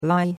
lai1.mp3